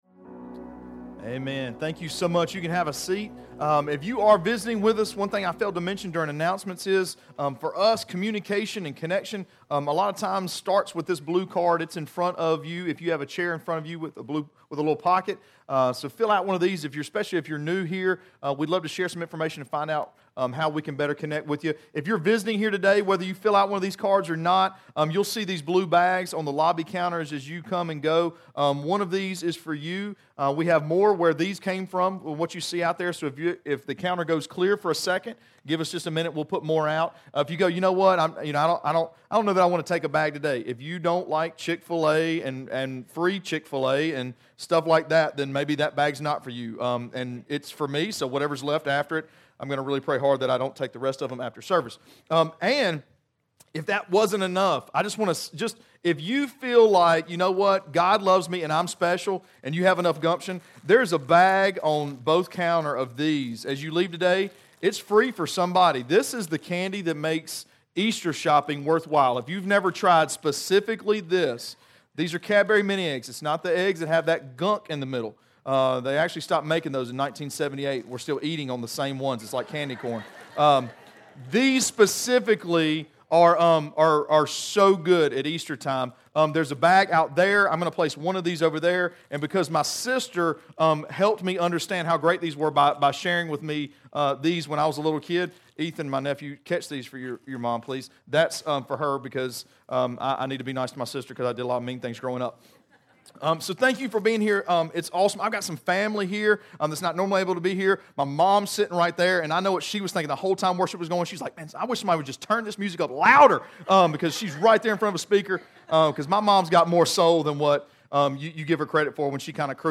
Sermons Archive - Page 27 of 60 - REEDY FORK COMMUNITY CHURCH